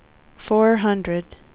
speech / tts / prompts / voices
number_123.wav